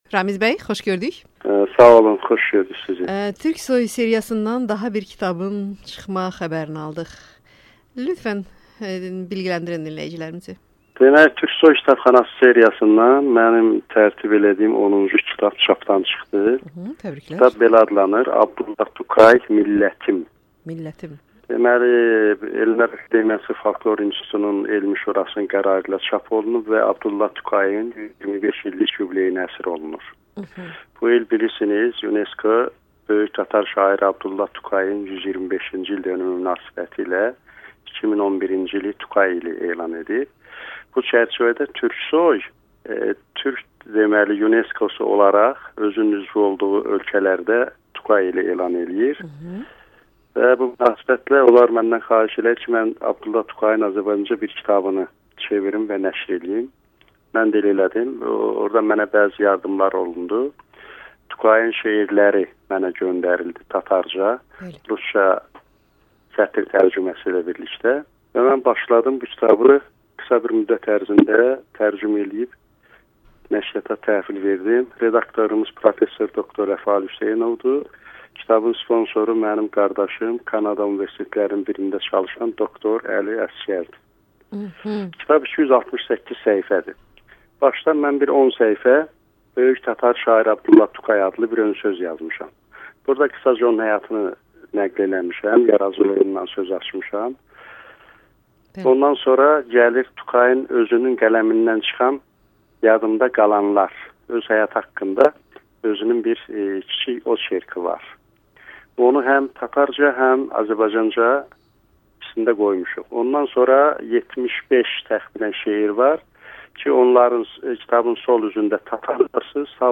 qısa müsahibə